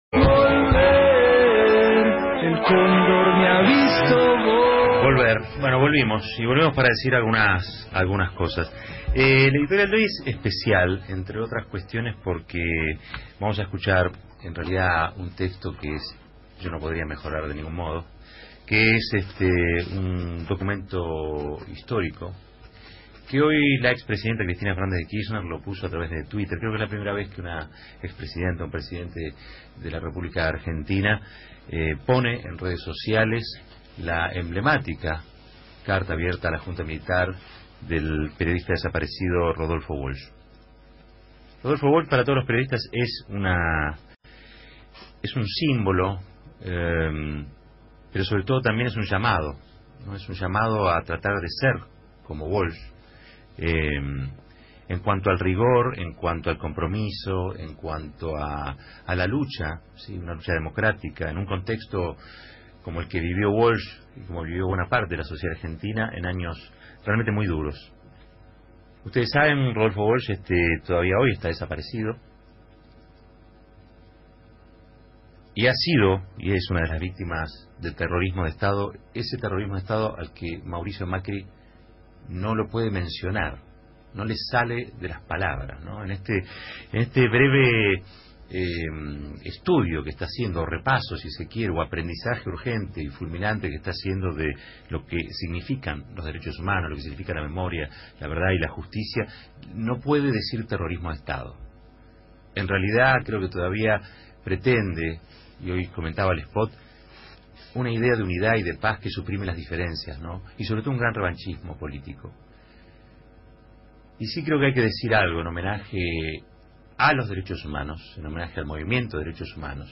editorial diario.